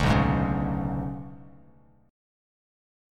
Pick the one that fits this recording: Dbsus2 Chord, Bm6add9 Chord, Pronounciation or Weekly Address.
Bm6add9 Chord